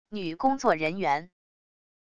女工作人员wav音频